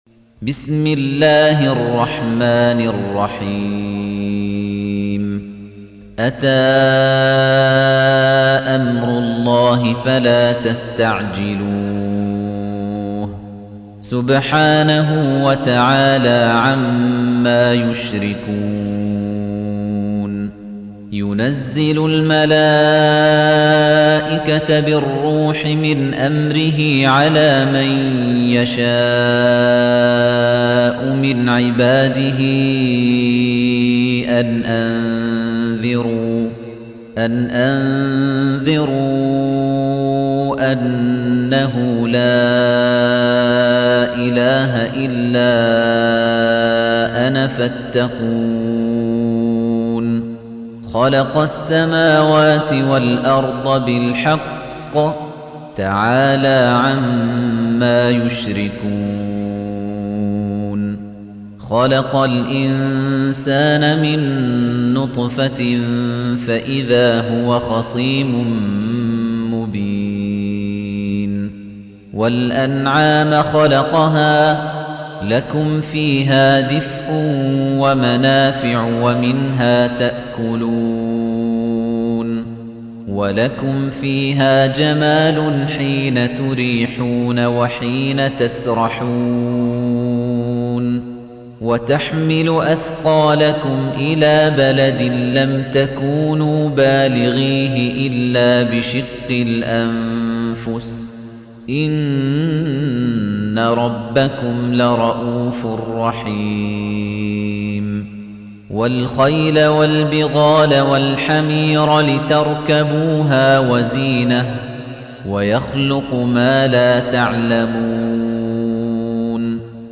تحميل : 16. سورة النحل / القارئ خليفة الطنيجي / القرآن الكريم / موقع يا حسين